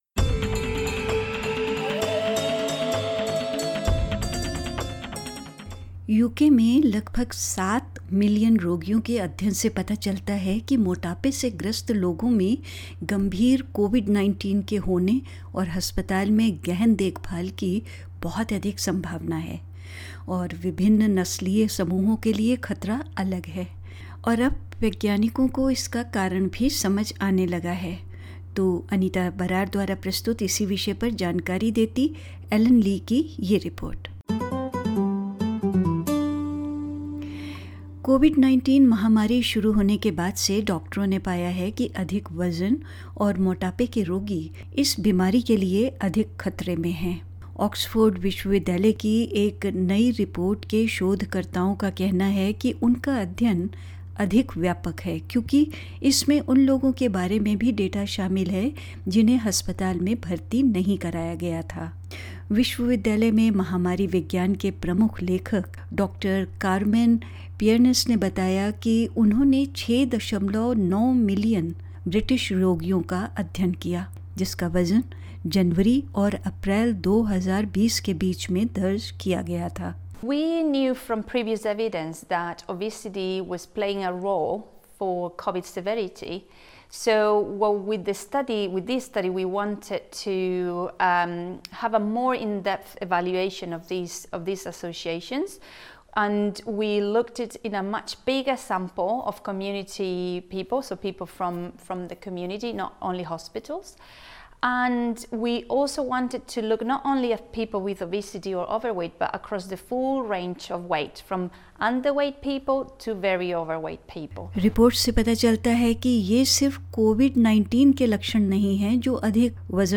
This report explains it.